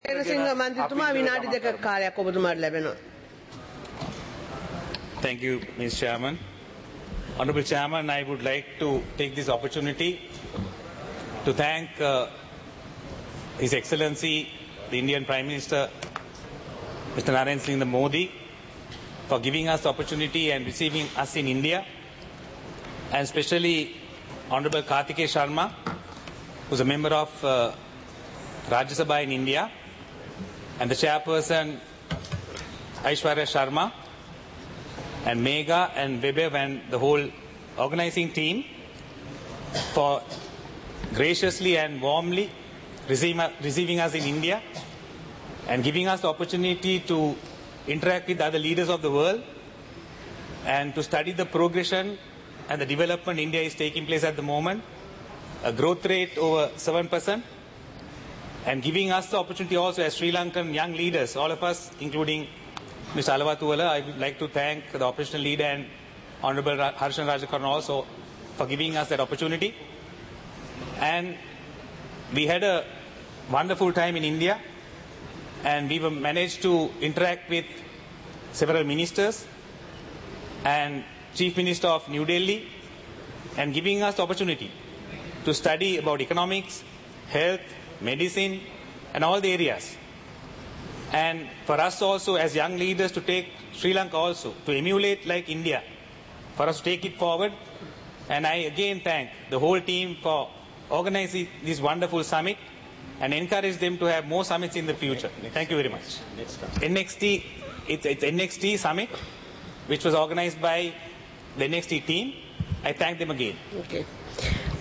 சபை நடவடிக்கைமுறை (2026-03-19)
நேரலை - பதிவுருத்தப்பட்ட